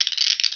walk1.wav